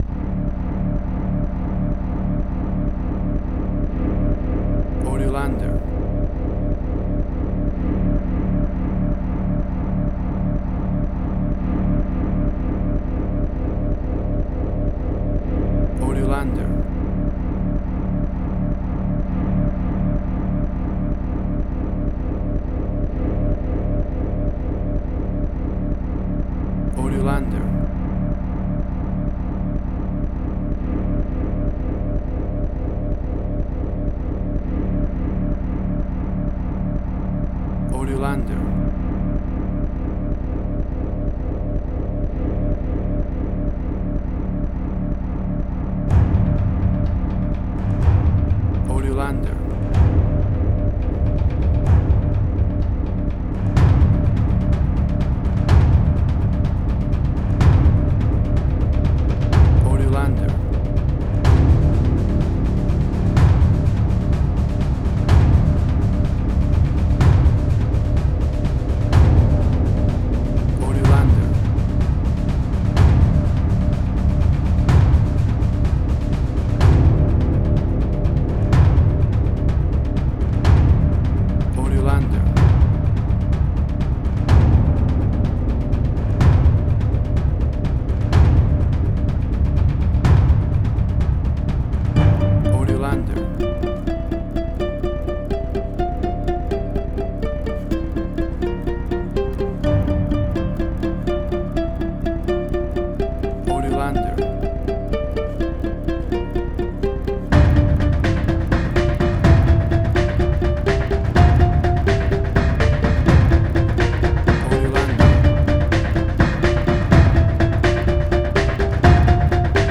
Suspense, Drama, Quirky, Emotional.
Tempo (BPM): 125